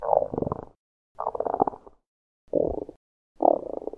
Звуки голода
На этой странице собраны разнообразные звуки голода: от тихого урчания до громкого бурчания пустого желудка.
Реалистичные звуки пищеварительной системы, которые точно передают ощущение голода.